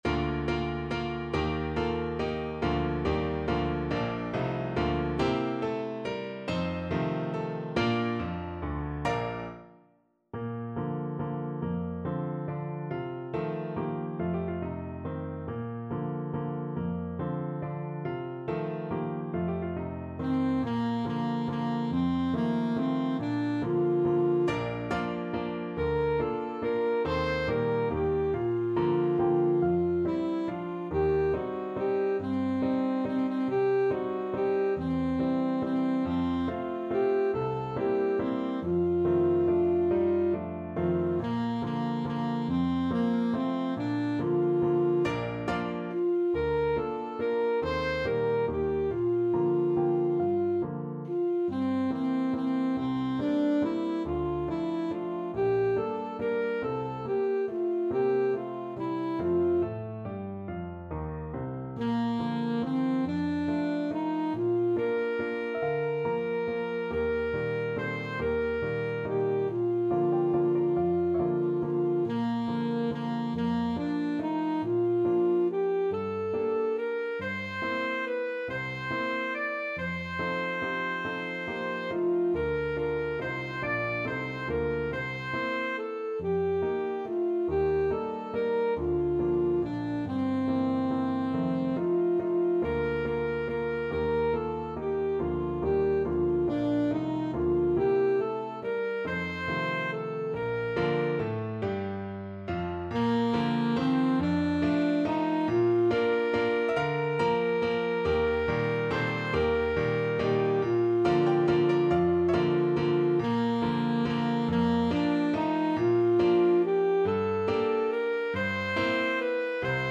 Alto Saxophone
3/4 (View more 3/4 Music)
~ = 140 Tempo di Valse